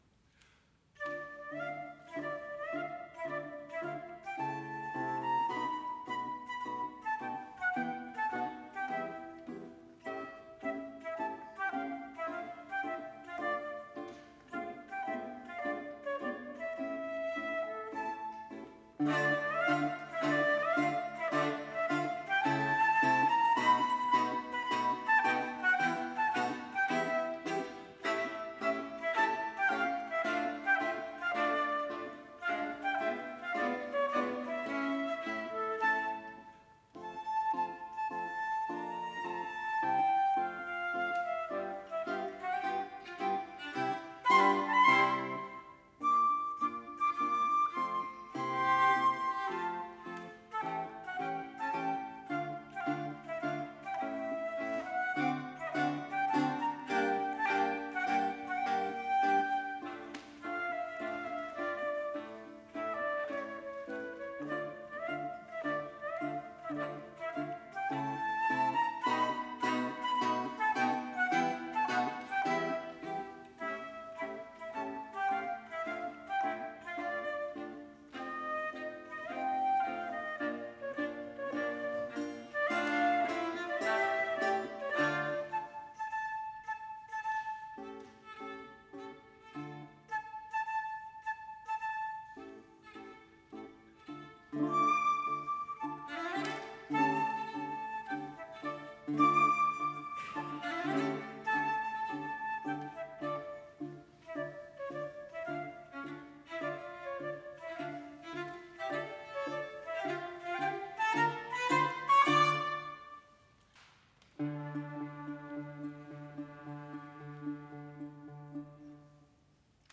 flauto
viola
chitarra
Cappella dei Mercanti, Torino
Marcia, Allegro
Torino. Novembre 2008. Live recording.